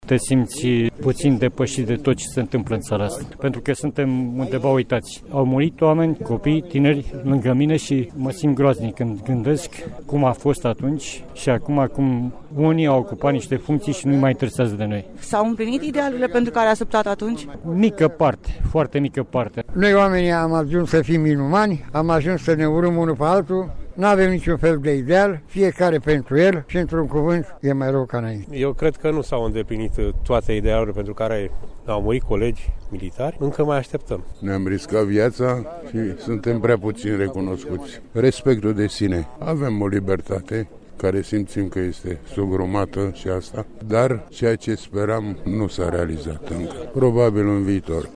De altfel, în Capitală, pe tot parcursul zilei de ieri, au fost organizate evenimente omagiale – mai întâi la monumentul din Piaţa Revoluţiei, apoi la Radiodifuziunea Română, la Troiţa de la Televiziunea Română şi la Palatul Telefoanelor. Au venit revoluţionari, rude ale celor care au murit, foşti militari chemaţi atunci să apere instituţiile publice.
vox-bucuresti.mp3